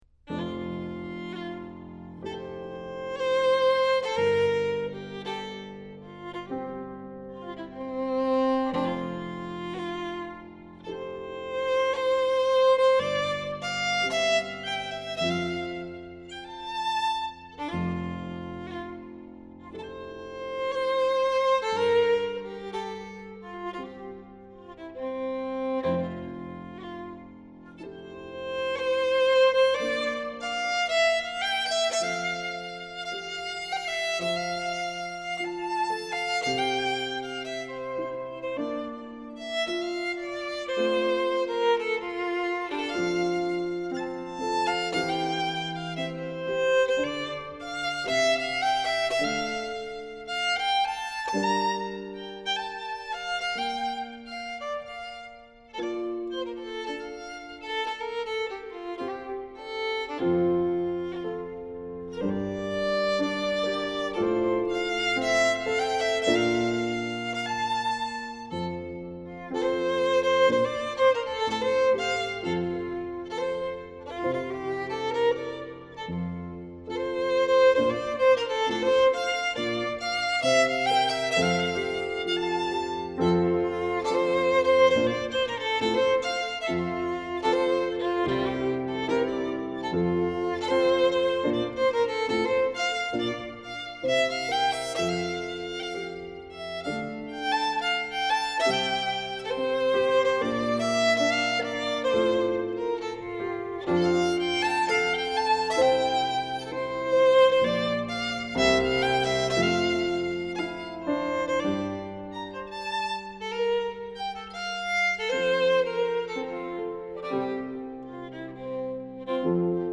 Scottish and Québécois fiddling.
au piano
à la guitare
à la flute, au flageolet et à la cornemuse
aux percussions